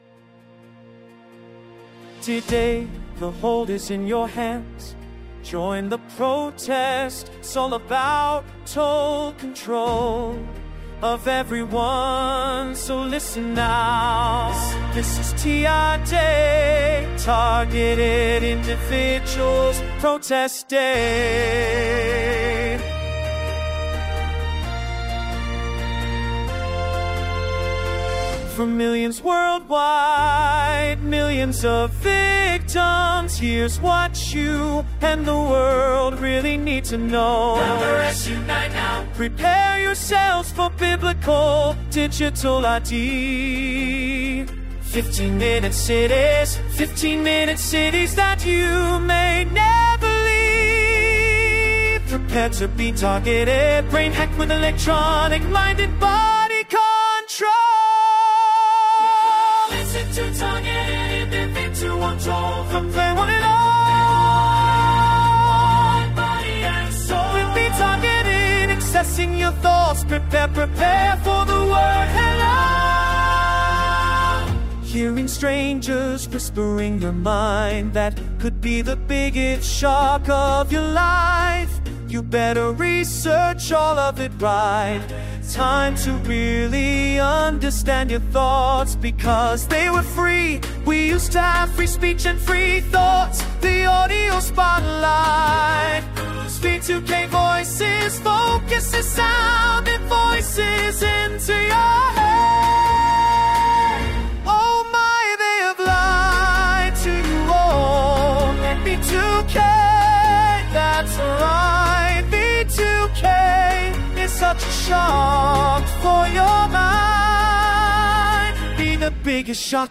HUGE ANTHEM
A MUSICAL SONG!